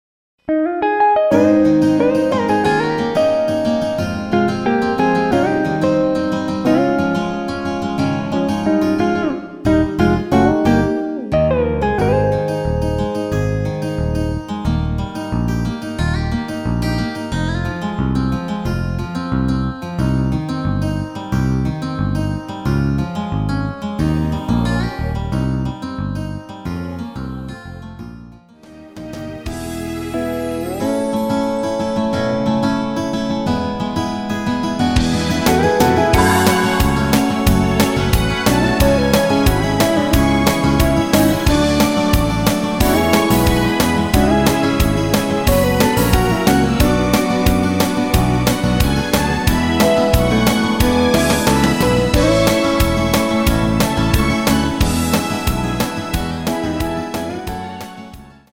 -2)내린 MR입니다.
키 Ab 가수